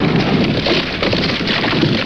Plaster Falling Breaking